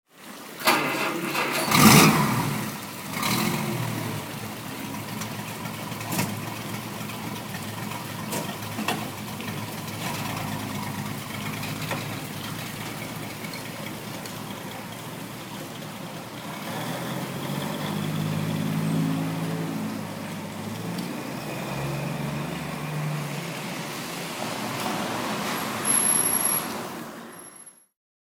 Motorsounds und Tonaufnahmen zu Kaiser DeLuxe Fahrzeugen (zufällige Auswahl)
Kaiser Deluxe (1951) - Starten und Rollen